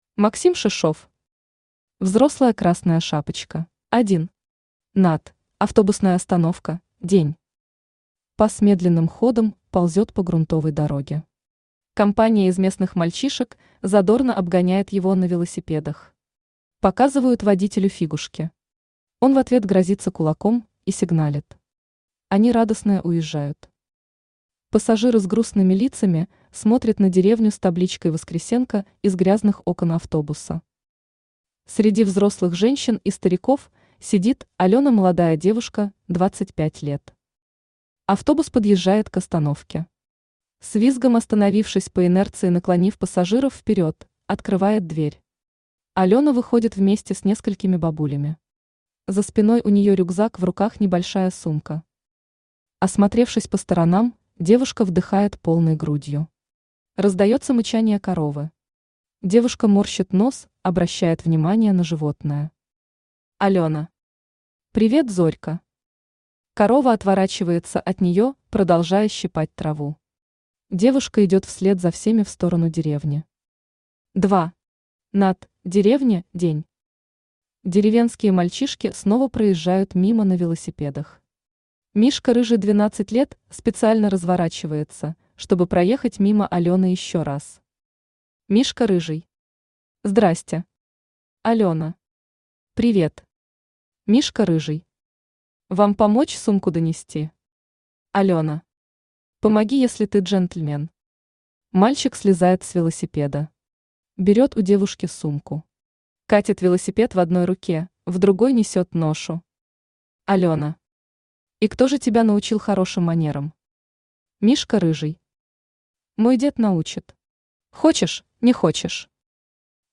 Аудиокнига Взрослая Красная Шапочка | Библиотека аудиокниг
Aудиокнига Взрослая Красная Шапочка Автор Максим Шишов Читает аудиокнигу Авточтец ЛитРес.